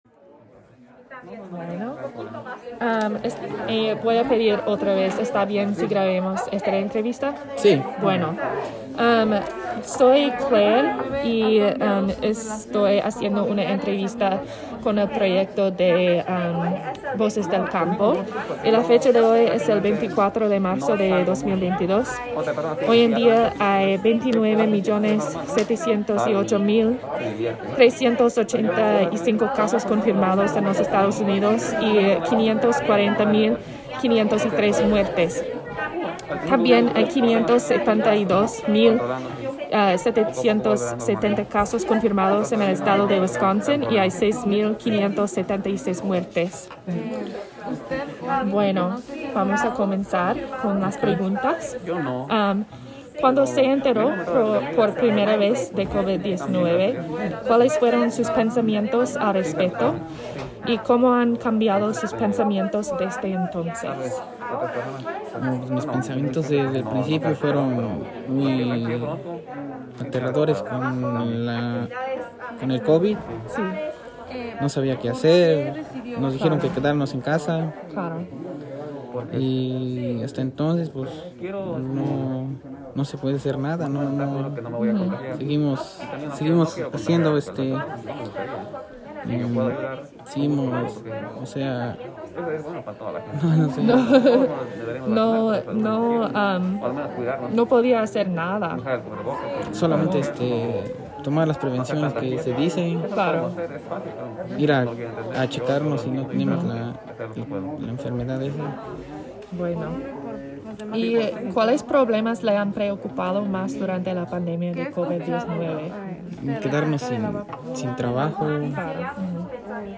Oral history, Anonymous farmworker. Nelson, Wis.
Entrevista grabada, Anónimo trabajador agrícola. Nelson Wis.